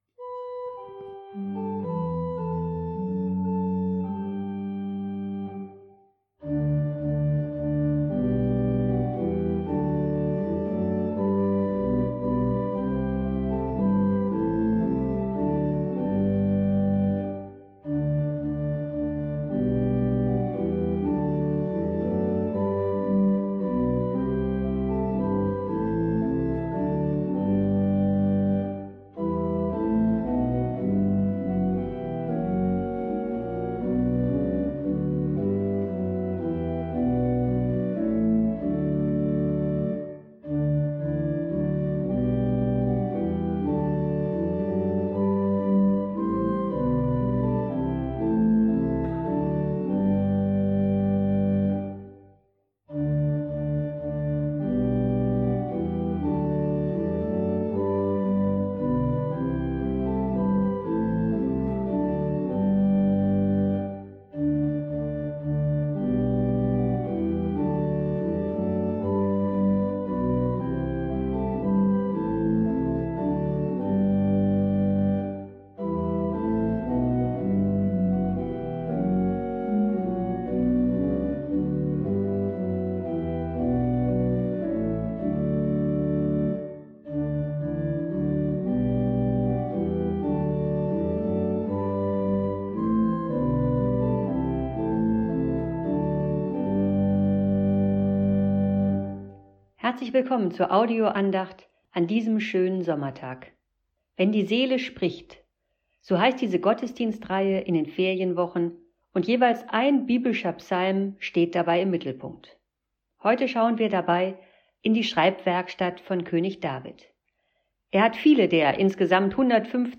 Psalmen. Wenn die Seele spricht… – Sommerpredigtreihe Teil 4